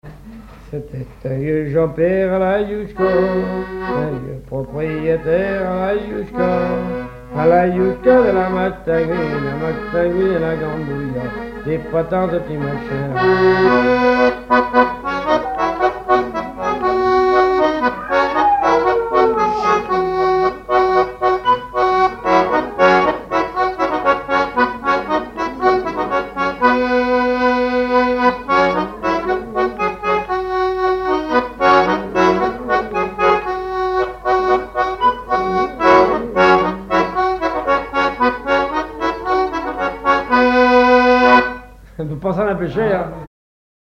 Saint-Hilaire-de-Riez
danse-jeu : youchka
accordéon diatonique
Pièce musicale inédite